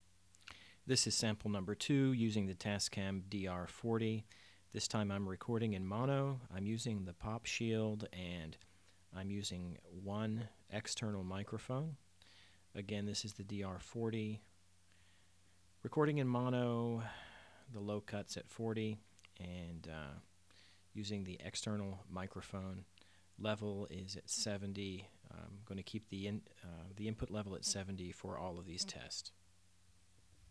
Audio Technica AT2020USB Cardioid Studio Condenser Microphone, samples recorded into Audacity, using a pop-filter (only one mic, even with Stereo recording).
2. Recorded in Mono, 16-bit WAV file.